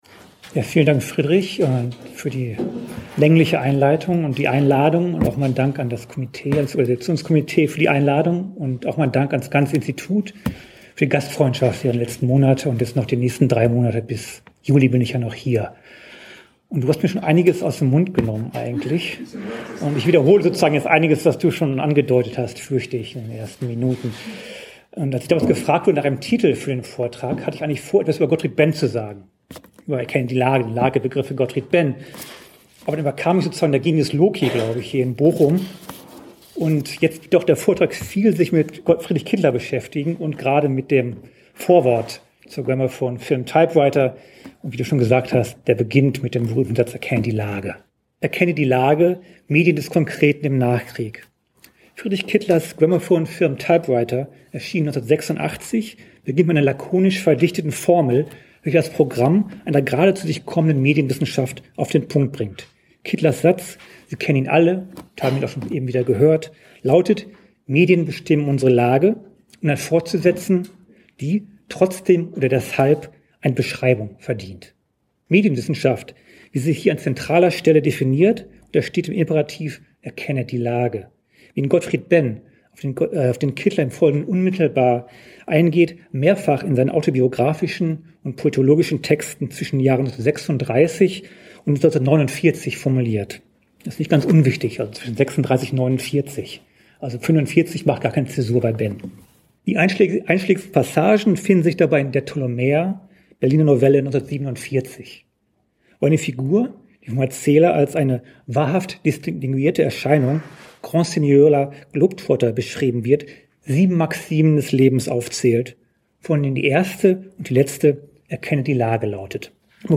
Der Vortrag wird den Hintergrund der von Kittler postulierten Bestimmtheit der Lage durch die Medien im literarisch-philosophischen Kontext der 1930er und 40er Jahre nachzeichnen.